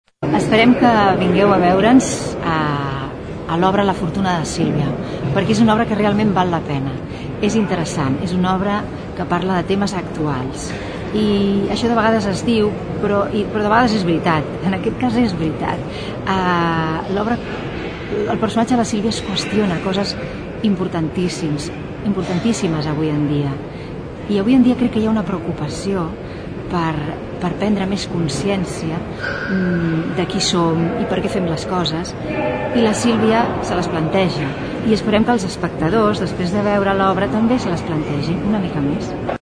L’actriu Laura Conejero ens parla del seu personatge a l’obra.